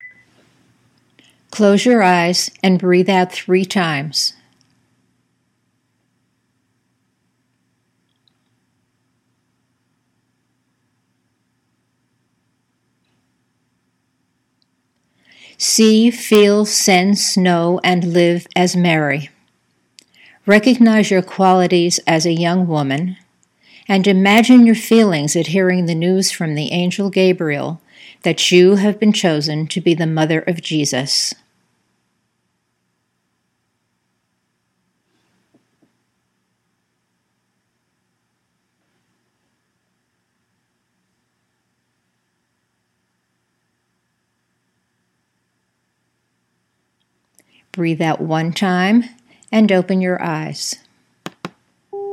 NOW FOR OUR ADVENT NARRATIVE WITH AUDIO IMAGERY (These exercises are not designed for young children.  Adults may use these narratives and/or Imagery as their Advent Wreath Prayers)